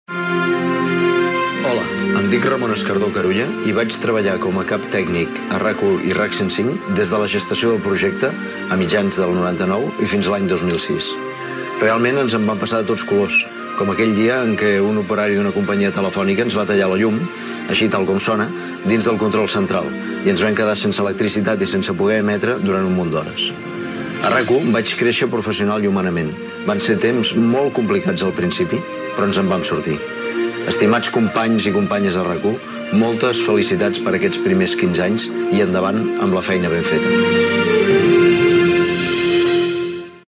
Felicitacions pels 15 anys de RAC 1.